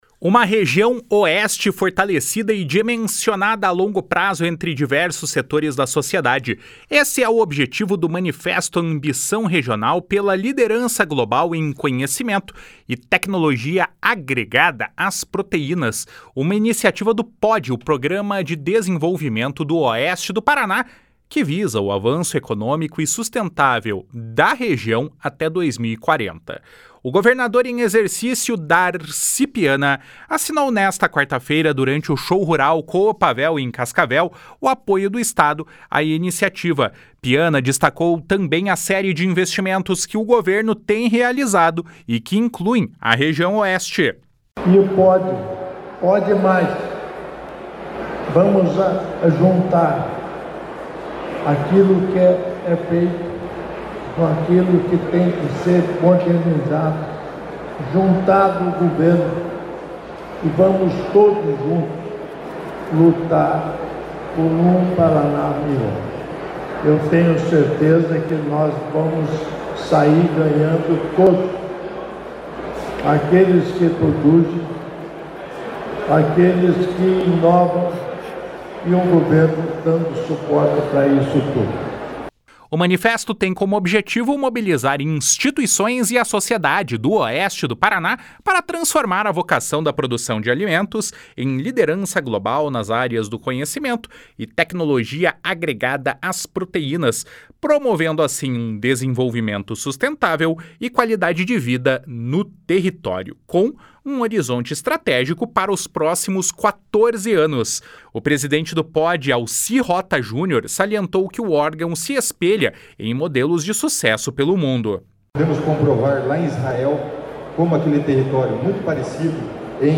O governador em exercício Darci Piana assinou nesta quarta-feira, durante o Show Rural Coopavel, em Cascavel, o apoio do Estado à iniciativa.
// SONORA DARCI PIANA //